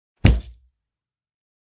meleeattack-impacts-bludgeoning-01.ogg